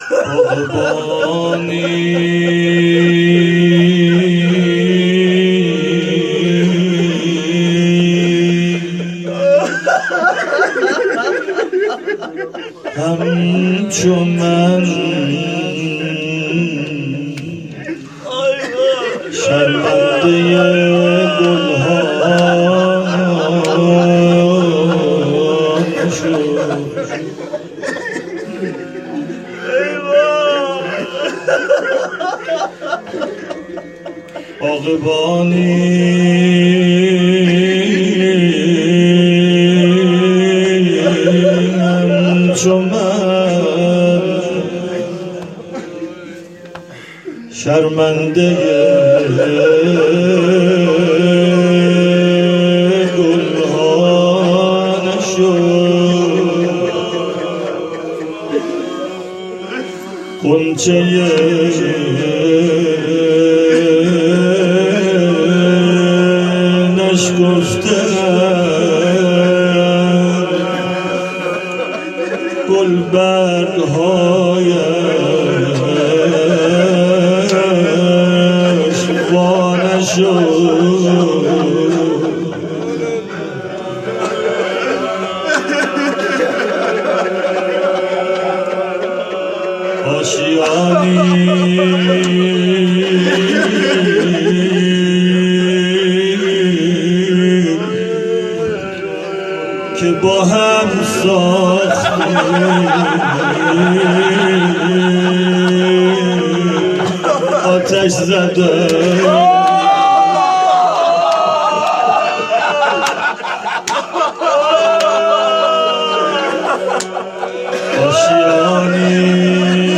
روضه - باغبانی همچو من